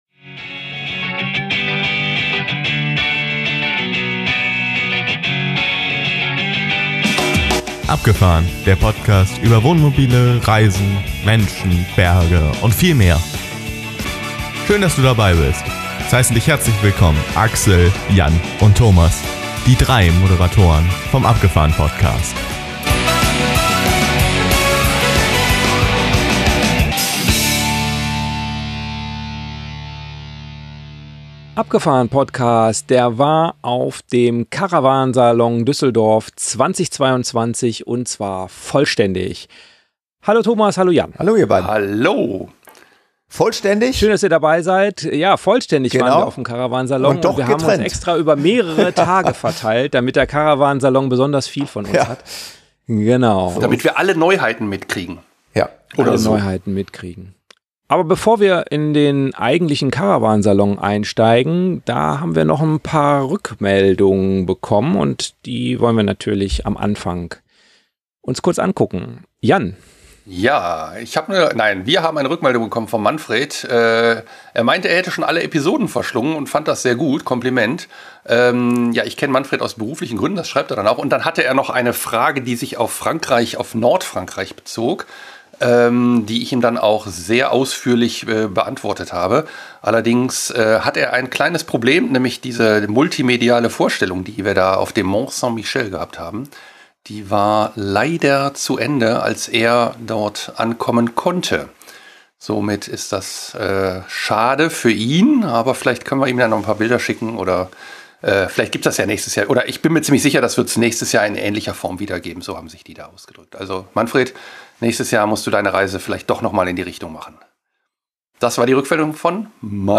Interviews, die in keine Video vorkommen, Informationen über das, was uns aufgefallen ist, Diskussionen über Wohnmobil-Kaufpreise, jede Menge gute Laune und einen tollen Ausblick auf 30 Tage Norwegen.